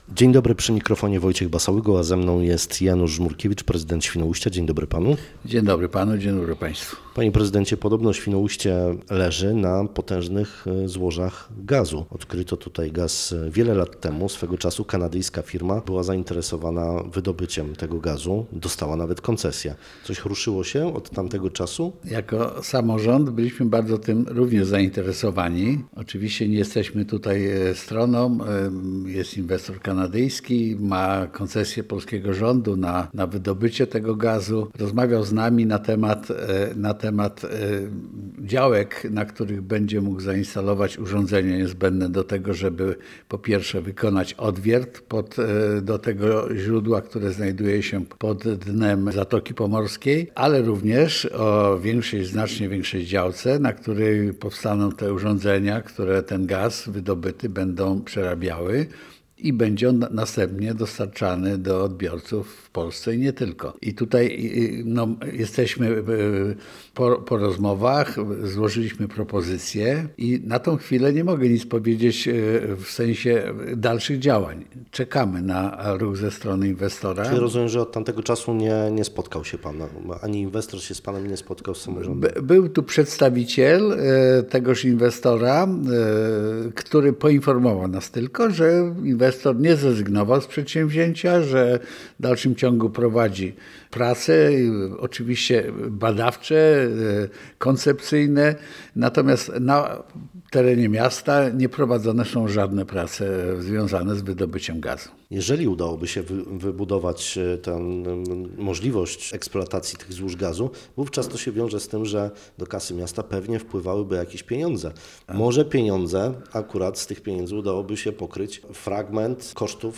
Dlatego naszym dzisiejszym gościem Rozmowy Dnia jest Janusz Żmurkiewicz, Prezydent Miasta Świnoujście, z którym poruszyliśmy ten temat. Rozmawialiśmy również między innymi o wzroście cen budowy tunelu w Świnoujściu.